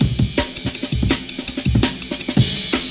10_break_amen_pprocessed.wav